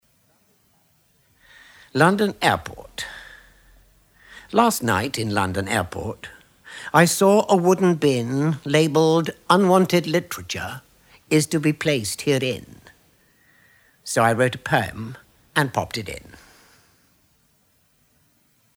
Recordings from a selection of 72 Poems on the Underground originally recorded on tape and published as a Cassell Audiobook in 1994
London Airport by Christopher Logue read by Christopher Logue